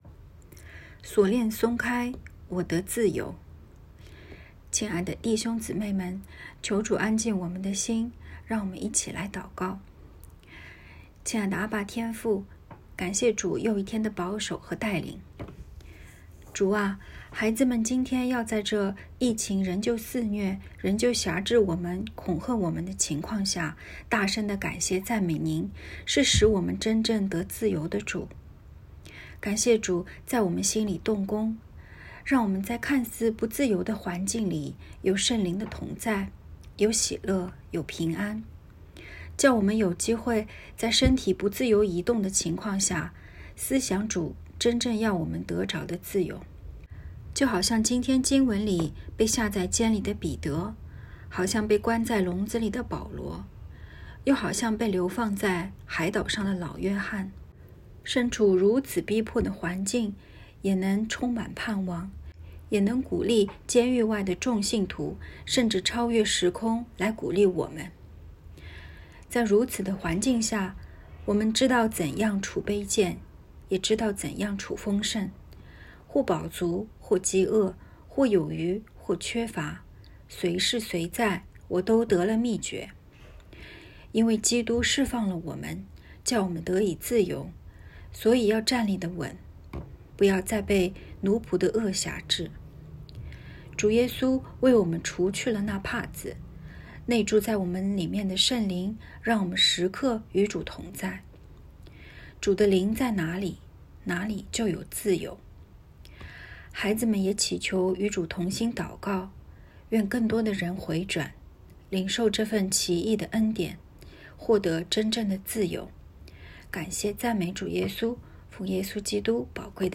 ✨晚祷时间✨2月7日（周一）